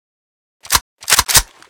akm_unjam.ogg